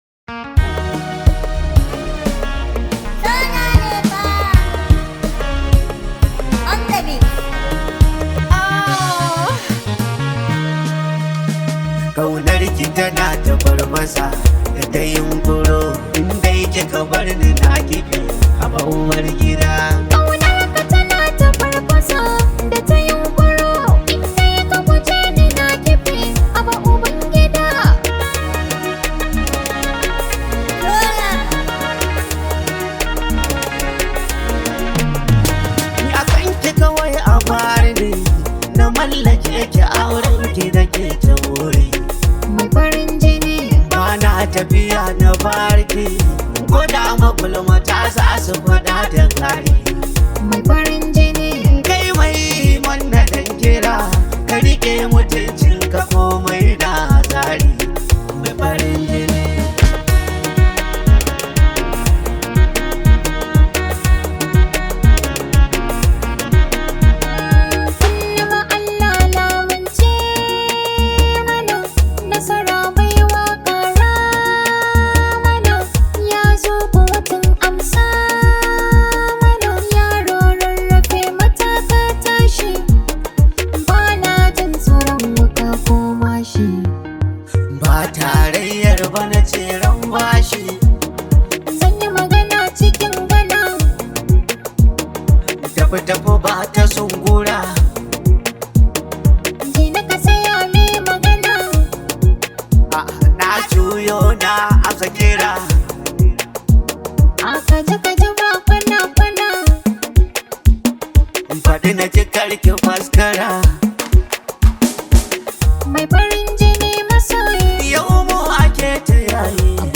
much appreciated hausa song known as
This high vibe hausa song